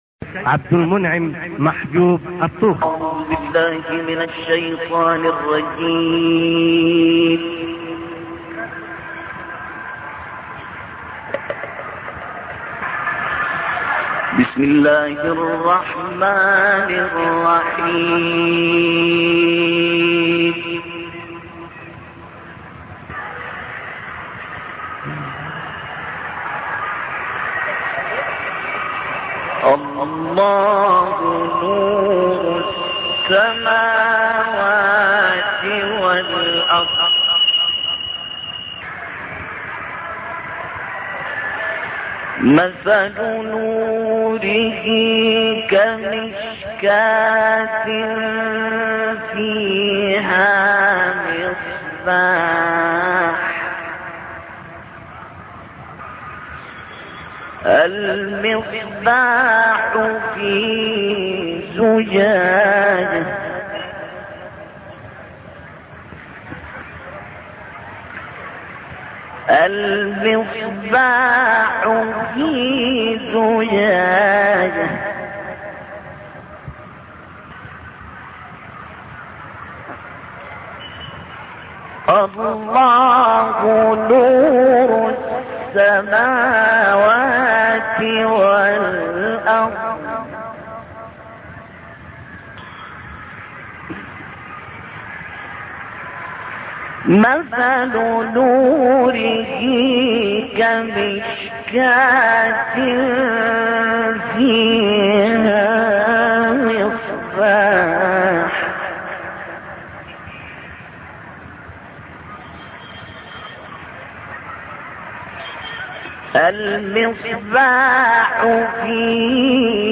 استماع وتحميل مباشر تلاوات خارجية للقارئ الشيخ القارئ عبد المنعم الطوخي عدد التلاوات ( 18 تسجيل بصيغة mp3 عالية الجودة )